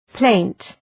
Προφορά
{pleınt}